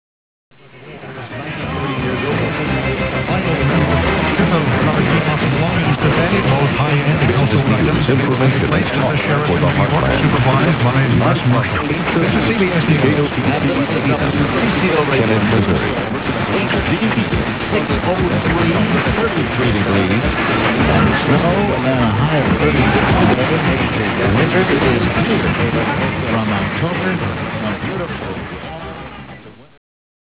This page contains DX Clips from the 2010 DX season!
KOTC Kennett, Missouri 830 heard at 6am mixed with WCCO. You can hear "...cbs news kotc..." as kotc fades above wcco briefly.